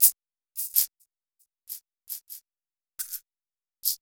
MARRRACASS-R.wav